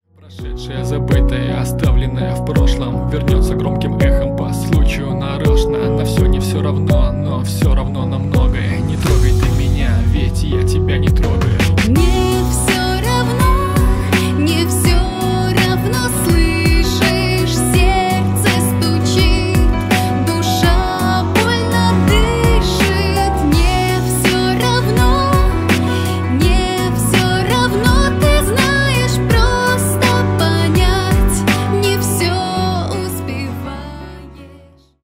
• Качество: 320 kbps, Stereo
Поп Музыка
спокойные